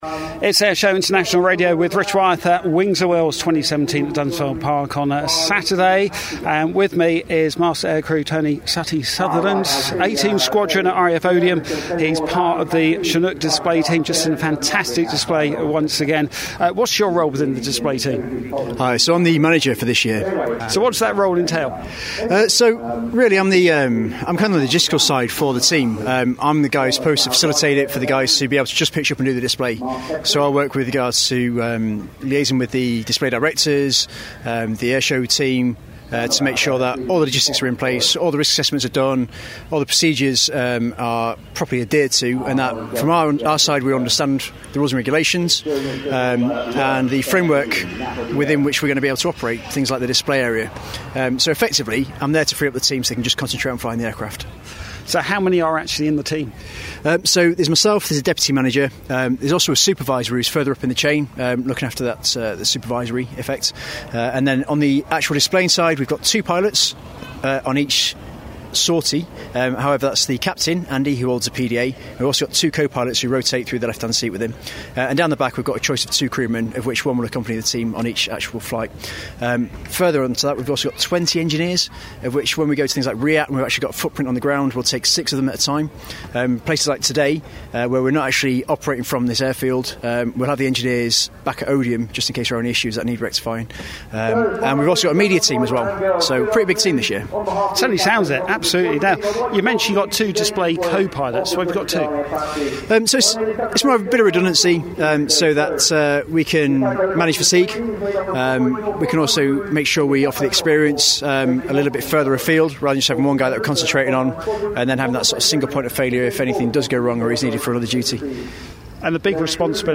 after their display on the Saturday of Wings & Wheels at Dunsfold Park